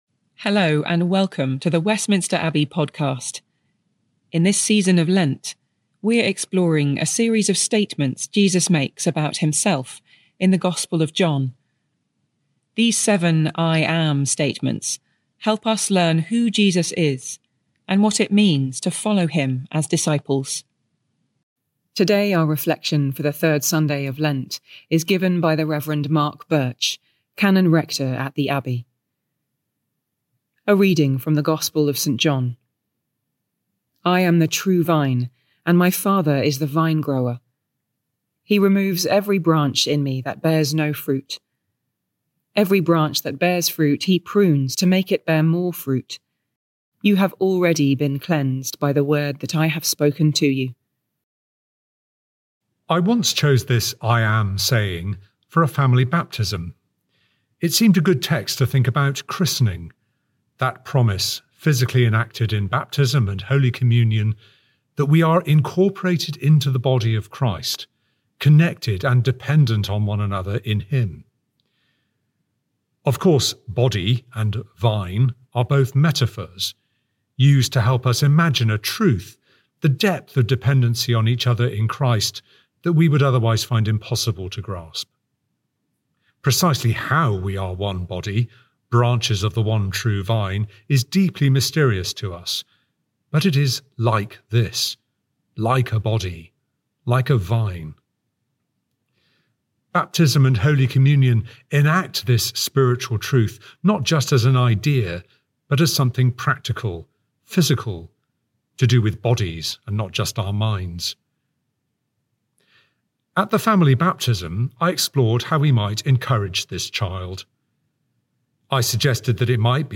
Listen to a reflection for the Third Sunday of Lent